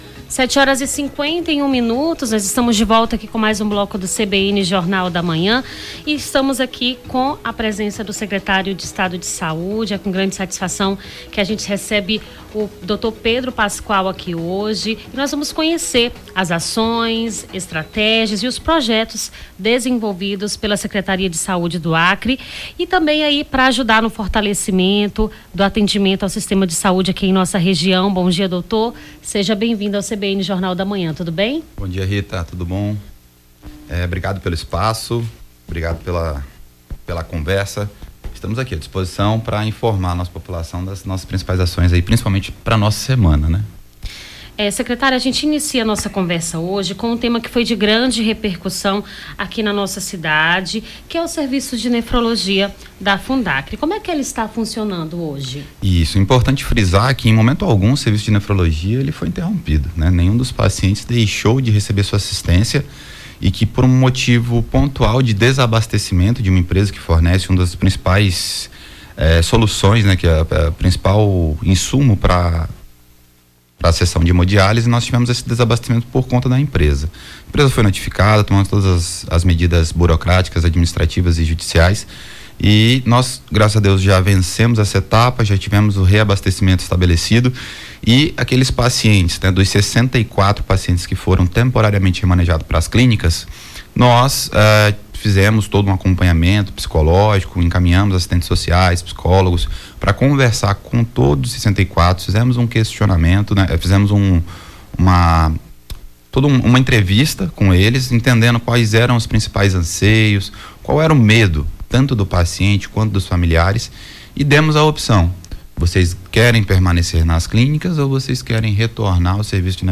Baixar Esta Trilha Nome do Artista - CENSURA- ENTREVISTA SECRETÁRIO DE SAÚDE ('11-11-24).mp3 Foto: arquivo pessoal Facebook Twitter LinkedIn Whatsapp Whatsapp Tópicos Rio Branco Acre Secretário de Saúde Sesacre Planos Ações